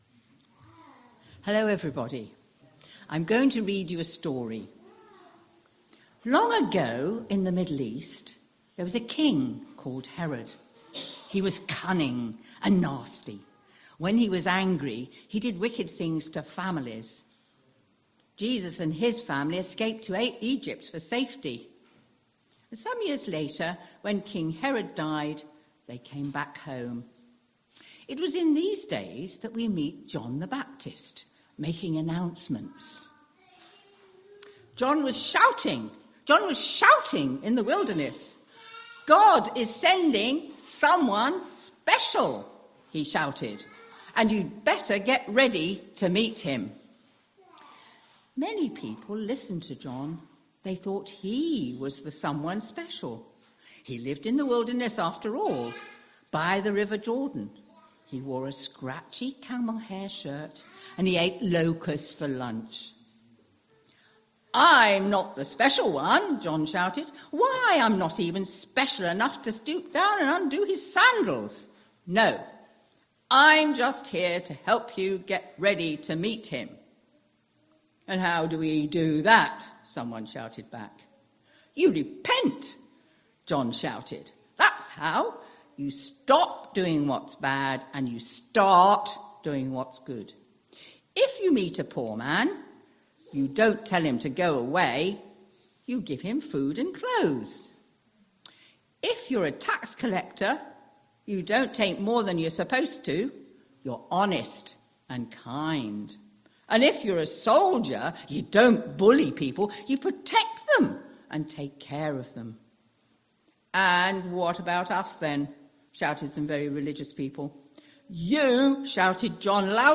3rd August 2025 Sunday Reading and Talk - St Luke's